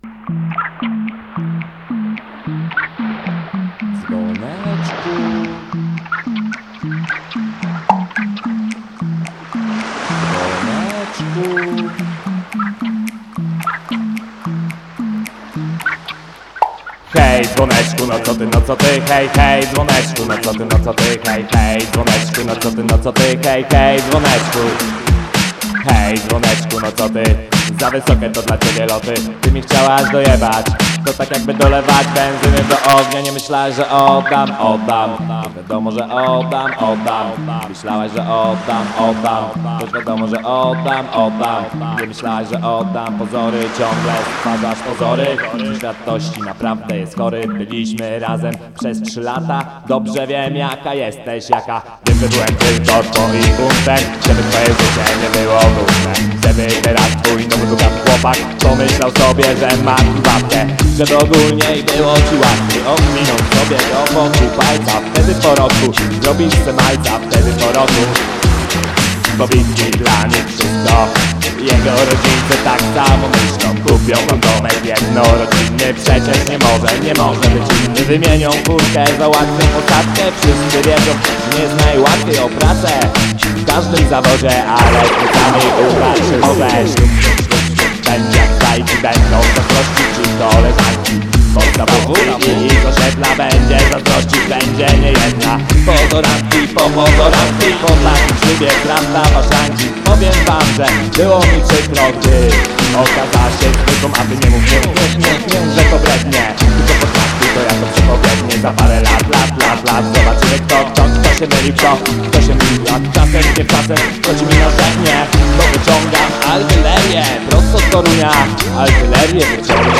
A hobby naszego dziargatora to robienie ragarapu.